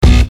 Kicks
nt electro kick 1.wav